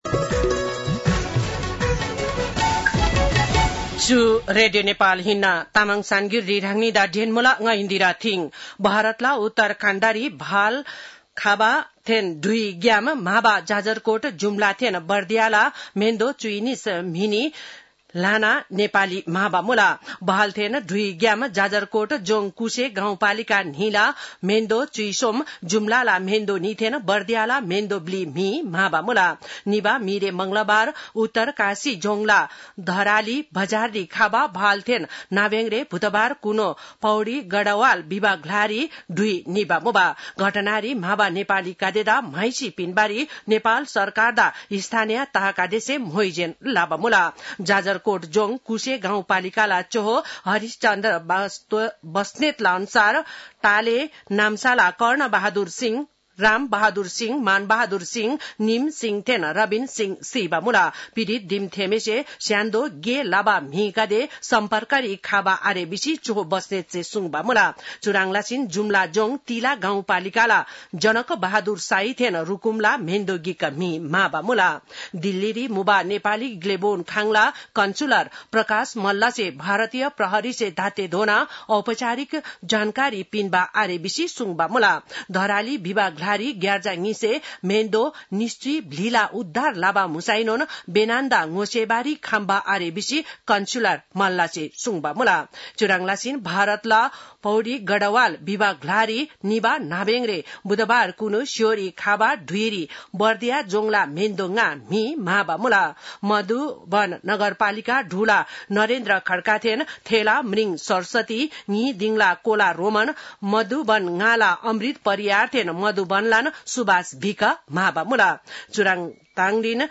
तामाङ भाषाको समाचार : २३ साउन , २०८२
Tamang-news-4-22.mp3